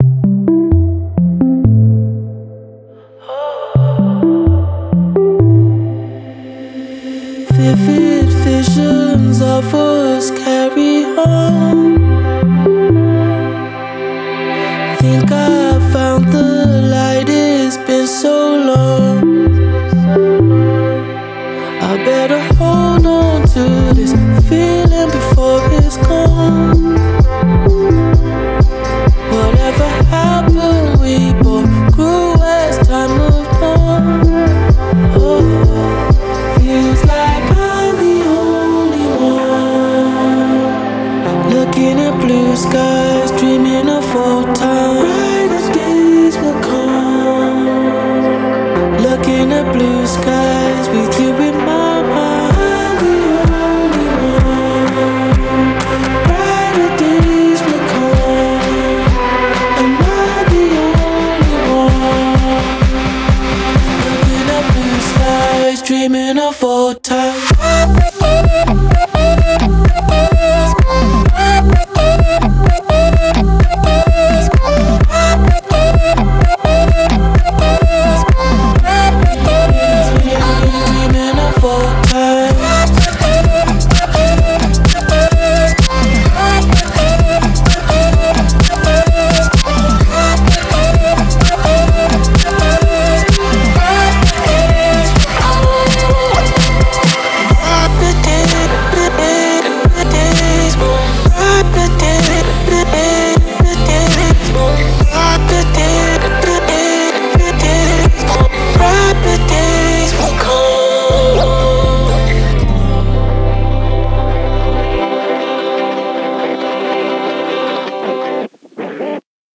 BPM64-128
Audio QualityMusic Cut
Still a fun chill beat, though.